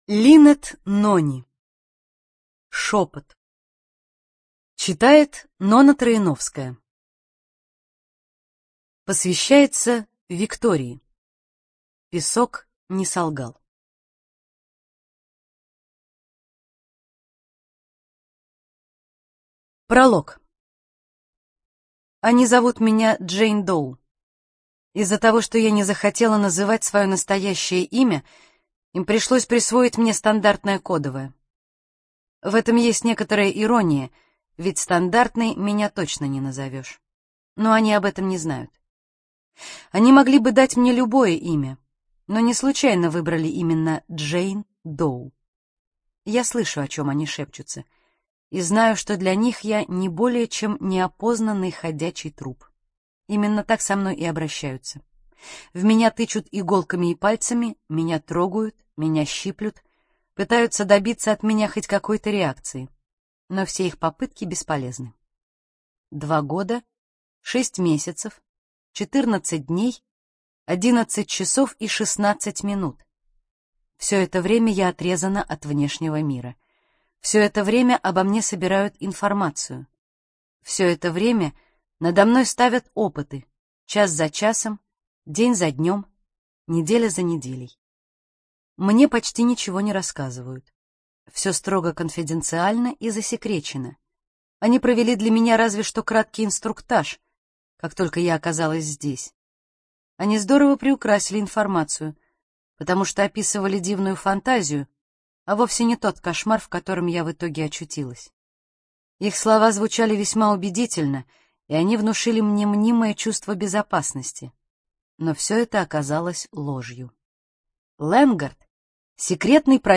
ЖанрФантастика, Детективы и триллеры